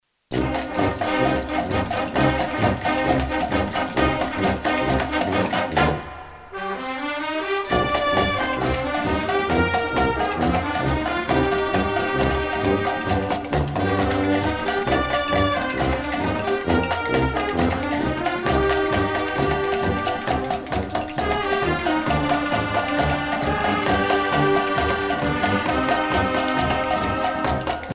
danse : samba